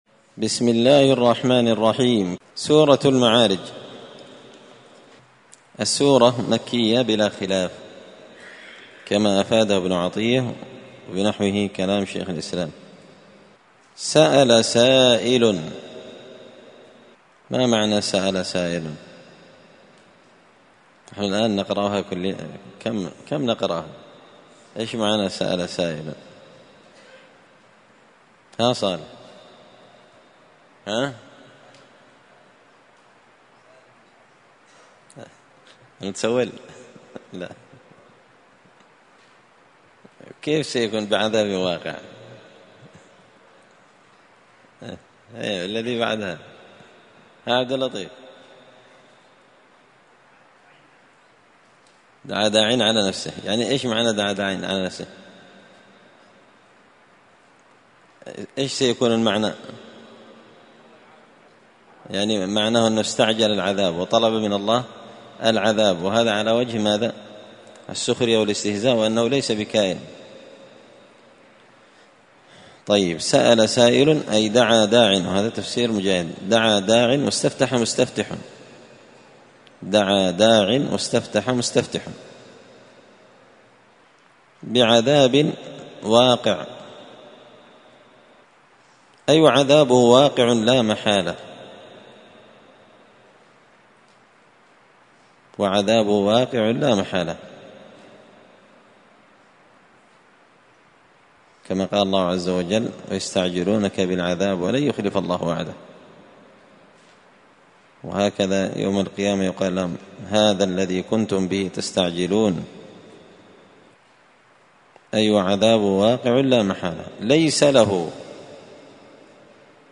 (جزء تبارك سورة المعارج الدرس 74)